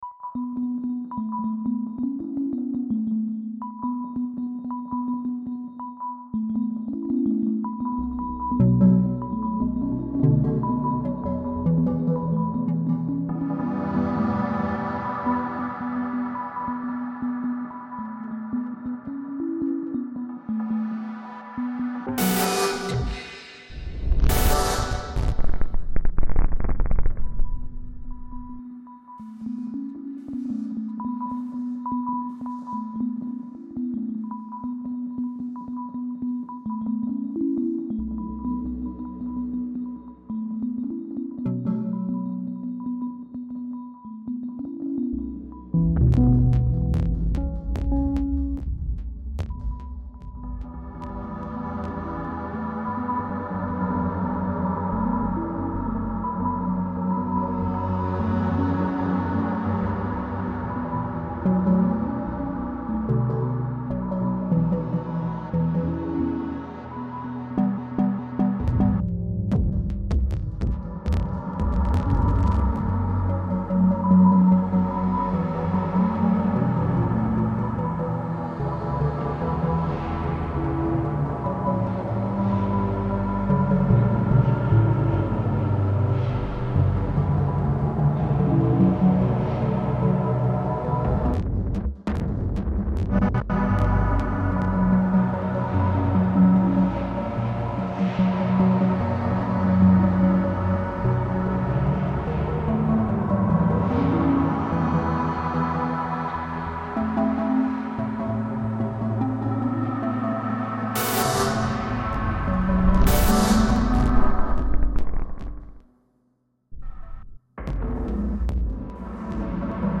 Cologne cathedral reimagined